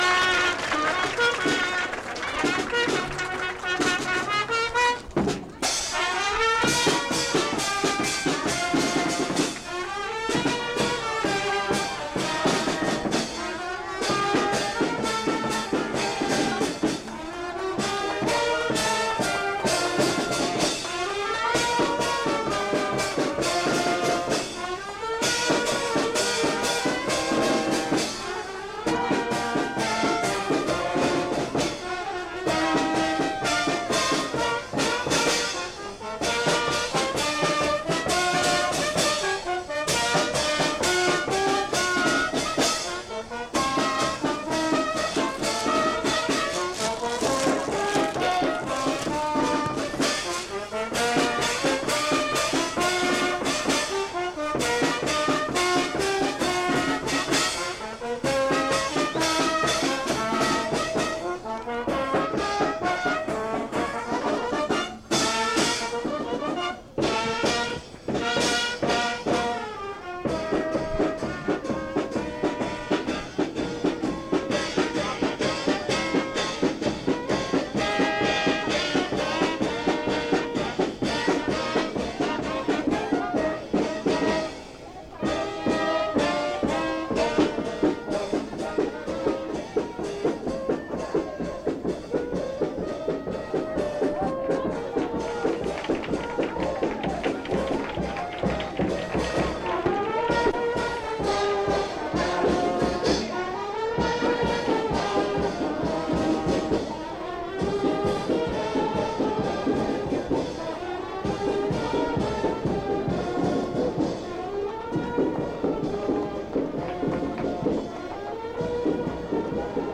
Fiesta de la Candelaria